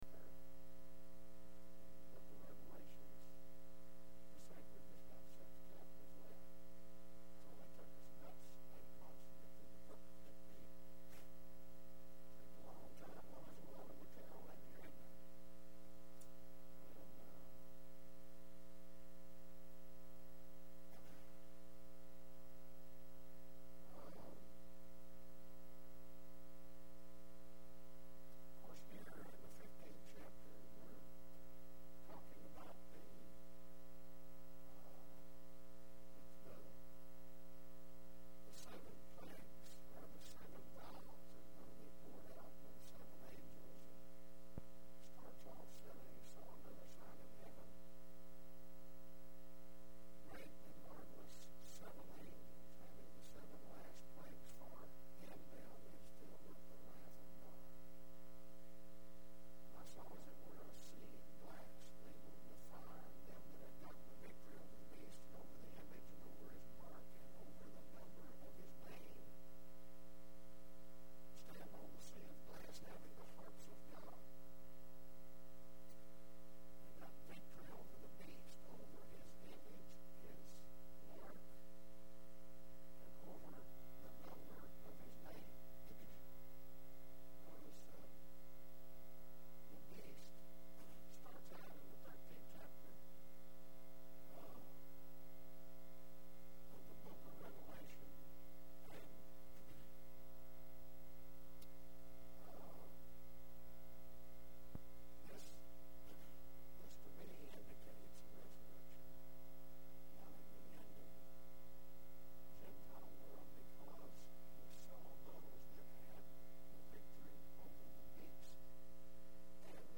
05/16/10 Sunday Services